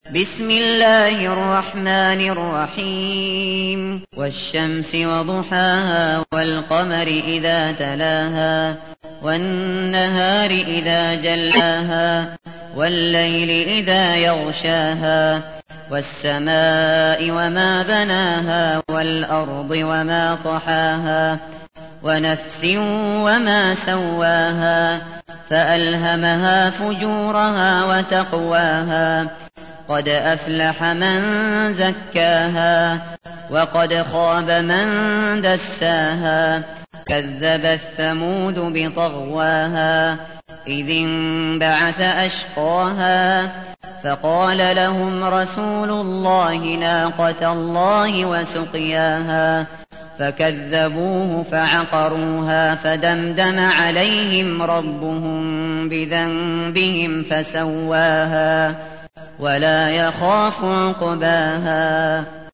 تحميل : 91. سورة الشمس / القارئ الشاطري / القرآن الكريم / موقع يا حسين